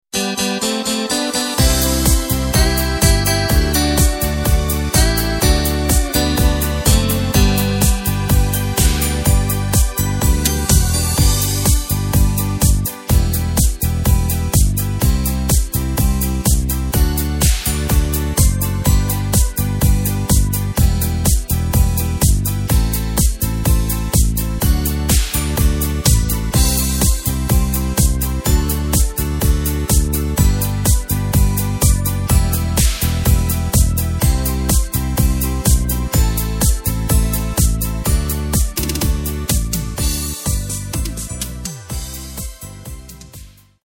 Takt:          4/4
Tempo:         125.00
Tonart:            G
Discofox aus dem Jahr 2017!
Playback mp3 Demo